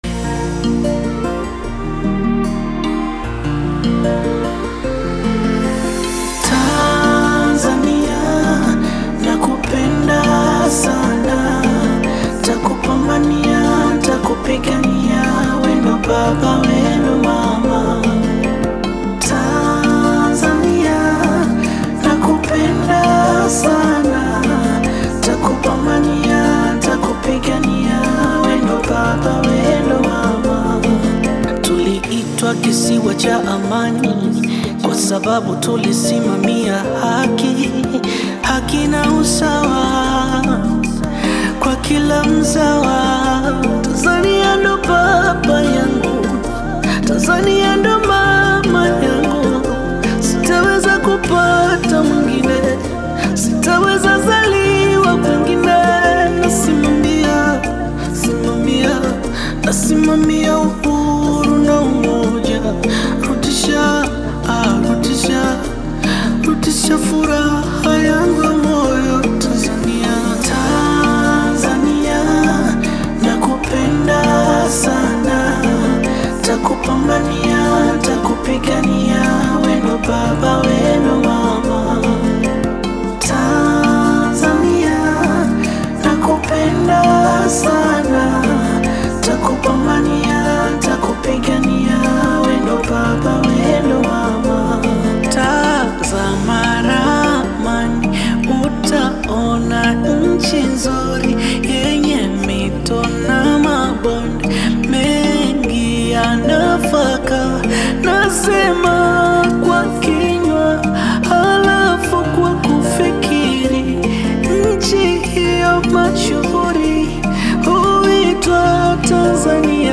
patriotic anthem